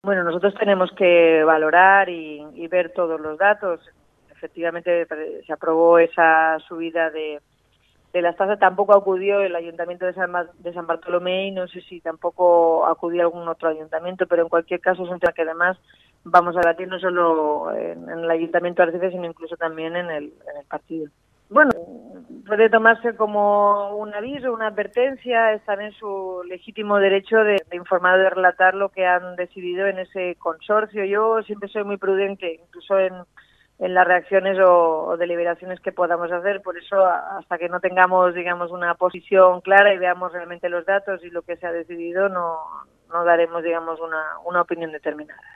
Cabe destacar que, además de la postura de San Bartolomé, esta semana también la alcaldesa de Arrecife, Eva de Anta, declaró en Cope Lanzarote que "todavía tenemos que estudiar bien el asunto para confirmar una postura concreta sobre el canon eólico" que reclama San Bartolomé al Consorcio.